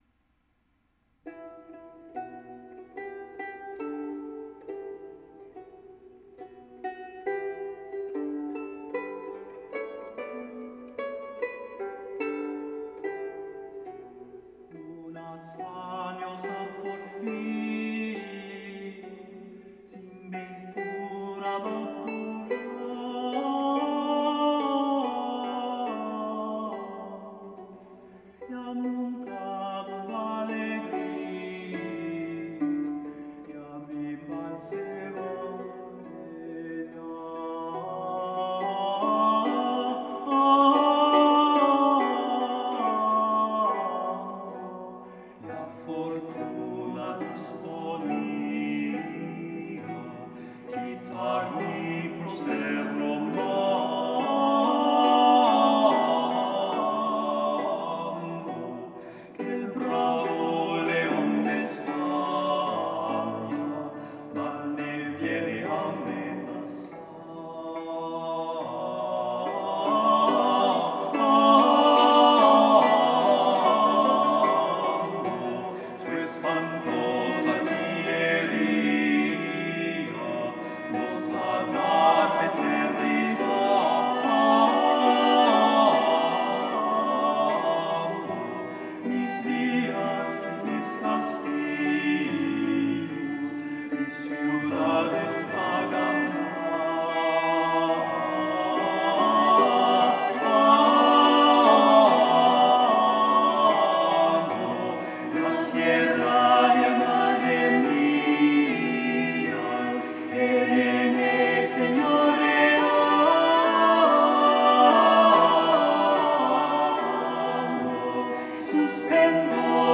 Una Sañosa Porfia by Juan del Encina, as recorded by Trio LiveOak on Star Shining on the Mountain, 1980, Mnemosyne Records, at the Groton Chapel in Groton MA
02-Una-sañosa-porfia-cornetto-4dB-Notre-dame.wav